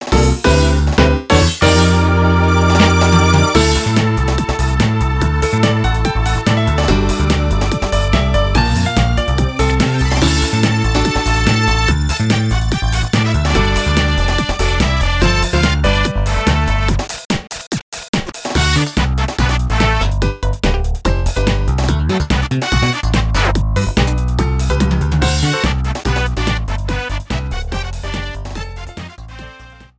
Ripped with Nitro Studio 2
Cropped to 30 seconds, fade out added